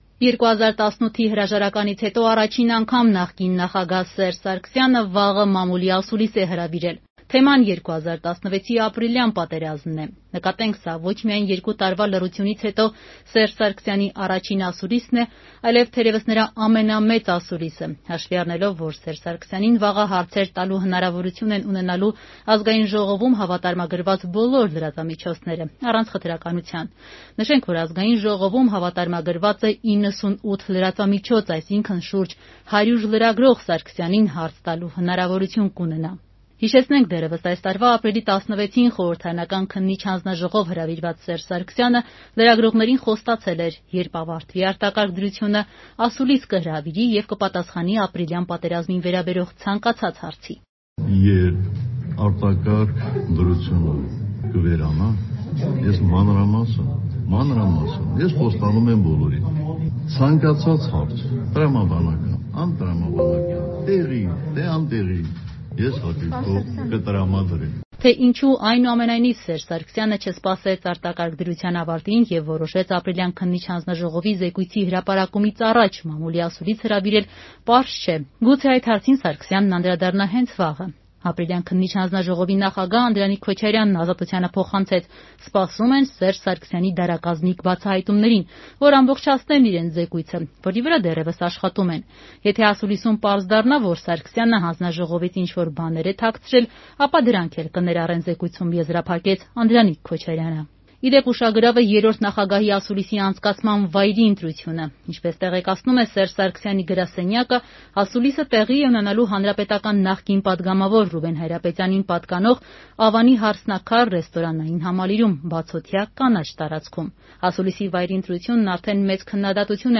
2 տարվա լռությունից հետո Սերժ Սարգսյանը ասուլիս է հրավիրել «Հարսնաքարում»
Ռեպորտաժներ